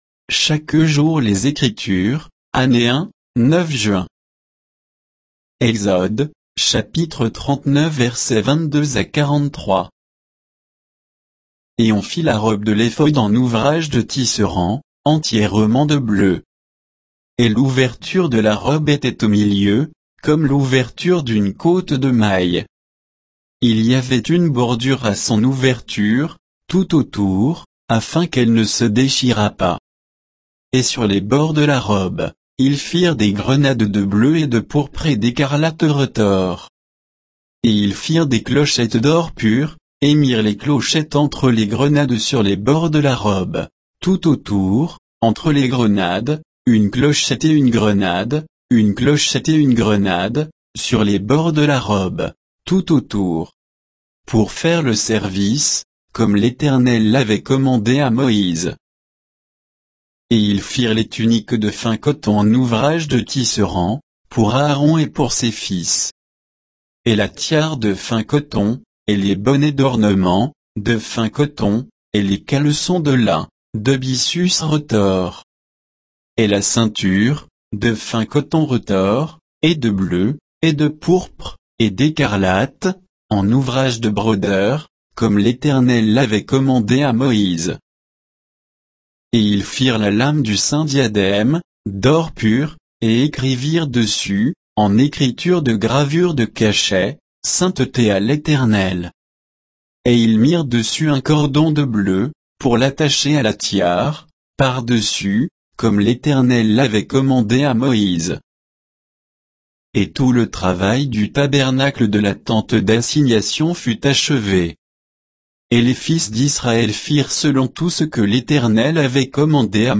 Méditation quoditienne de Chaque jour les Écritures sur Exode 39